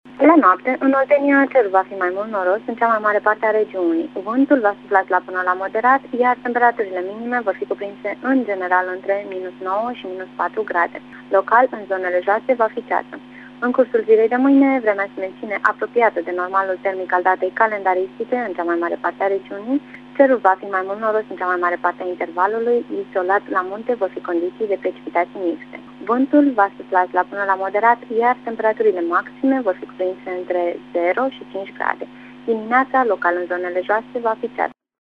Prognoza meteo